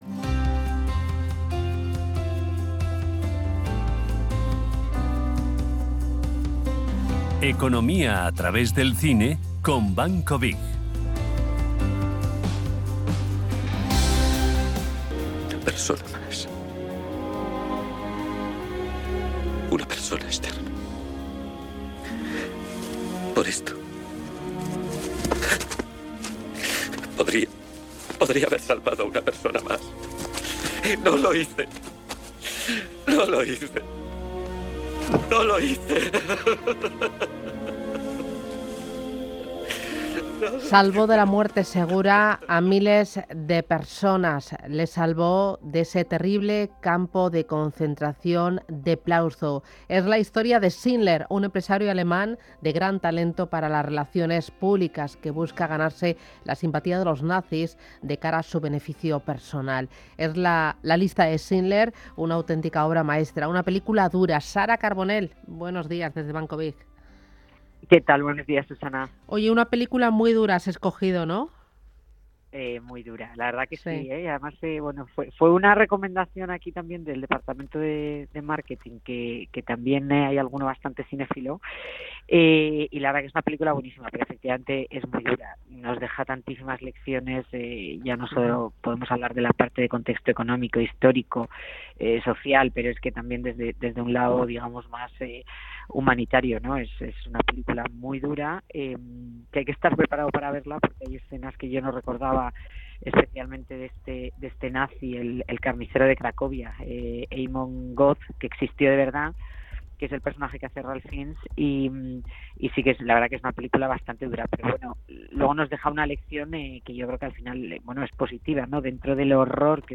Si quieres saber más, aquí tienes disponible el corte radiofónico de la sección.